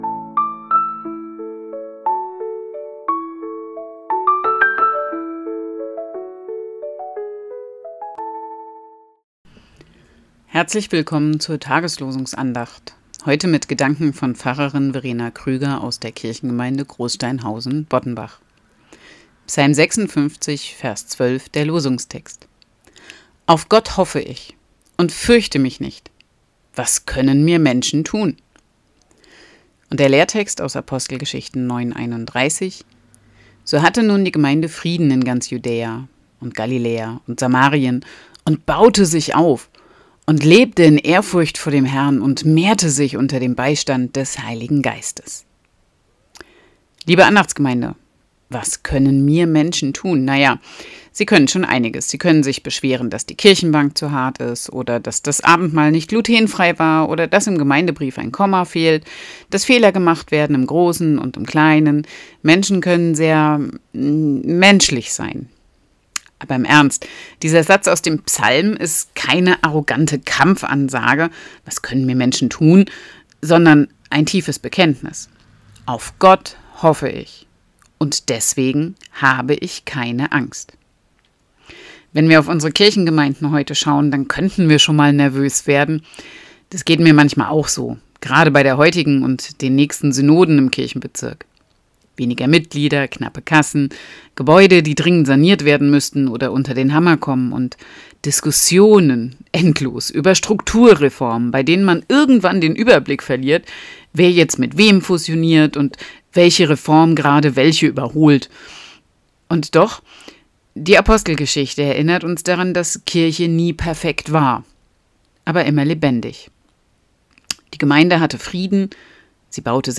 Losungsandacht für Samstag, 13.09.2025 – Prot. Kirchengemeinde Hornbachtal mit der prot. Kirchengemeinde Rimschweiler